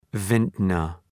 {‘vıntnər}